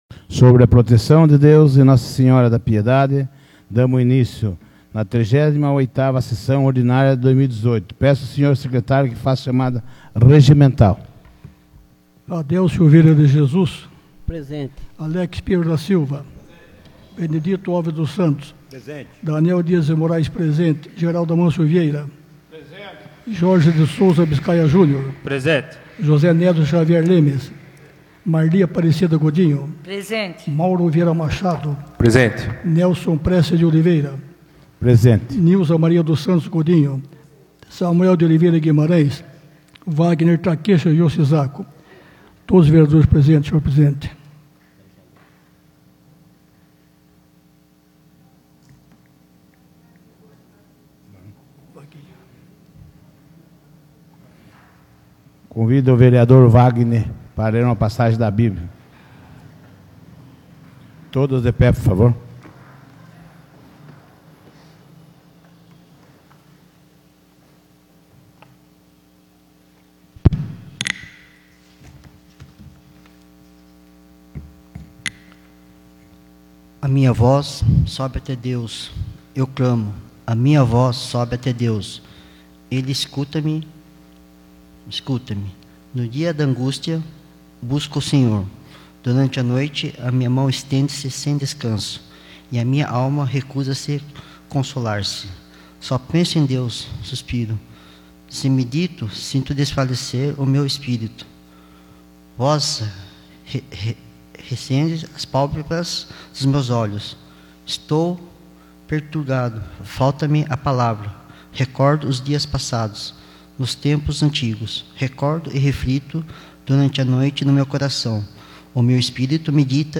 38ª Sessão Ordinária de 2018 — Câmara Municipal de Piedade